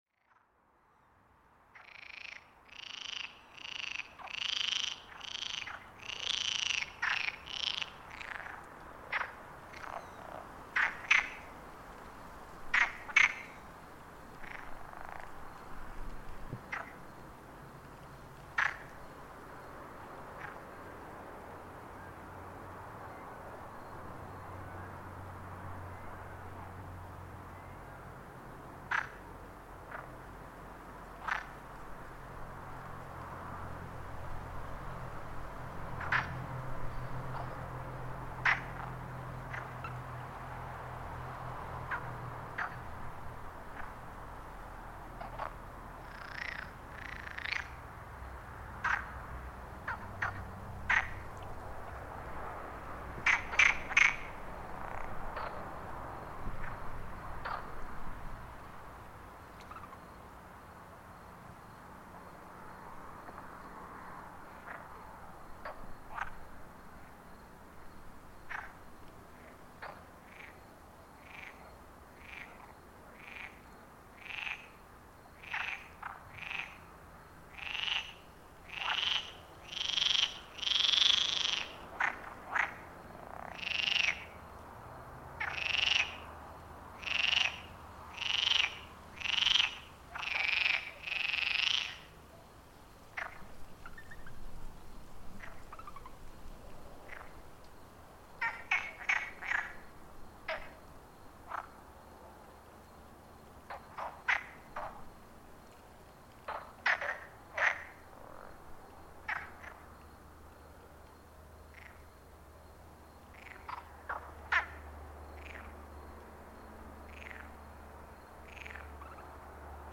The midnight frog chorus
At midnight by a rural pond on Sao Miguel in the Azores, dozens of frogs croak their nightly chorus uninterrupted, as a recording device is placed among them and left. All around the pond, voice rise rhythmically and in unison, creating a midnight chorus of frog calls.